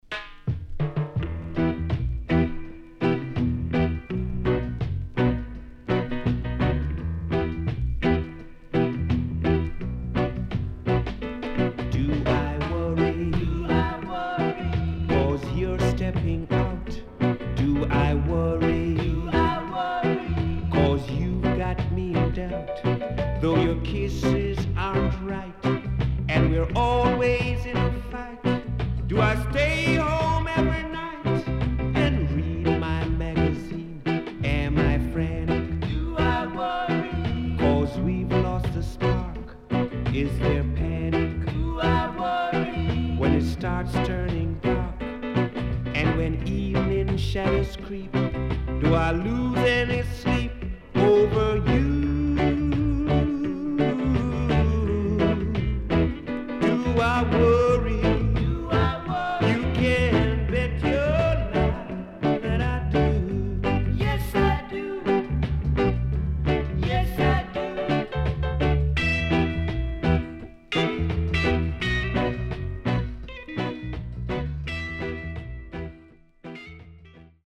SIDE A:少しチリノイズ入りますが良好です。
SIDE B:少しチリノイズ入りますが良好です。